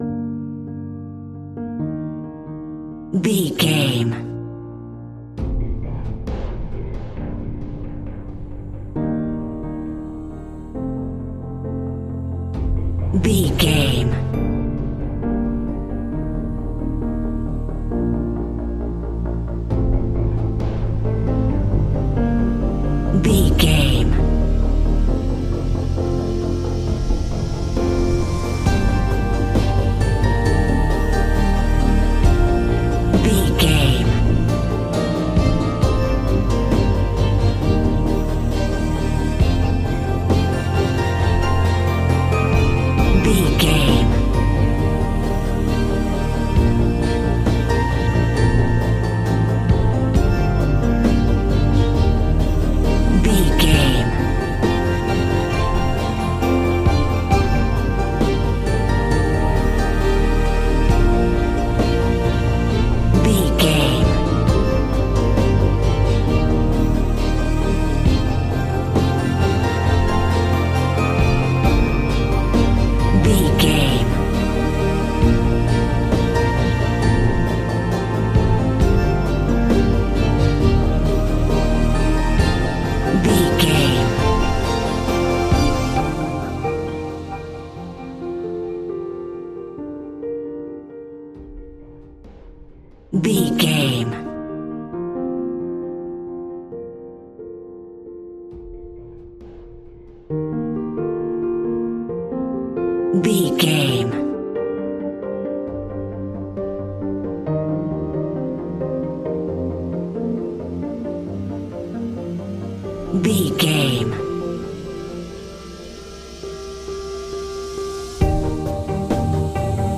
Aeolian/Minor
dramatic
epic
powerful
strings
percussion
synthesiser
brass
violin
cello
double bass